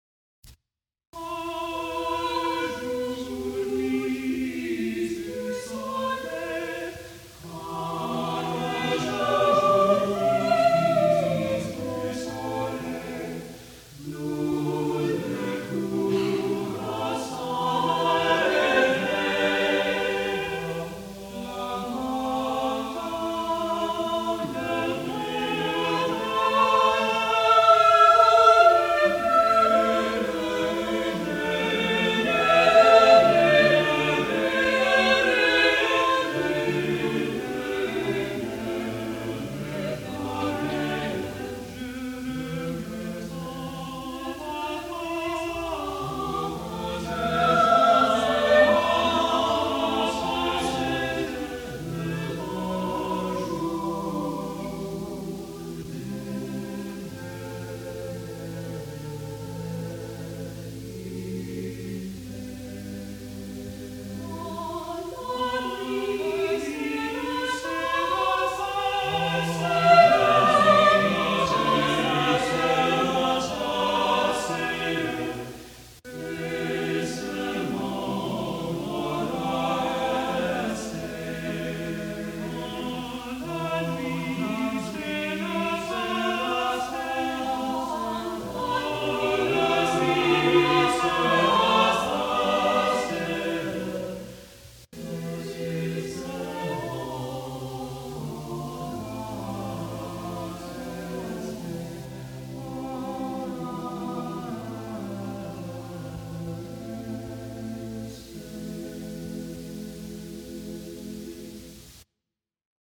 | Vocal Ensemble 'Seven Ages' 1979